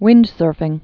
(wĭndsûrfĭng)